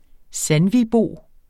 Udtale [ ˈsanviˌboˀ ]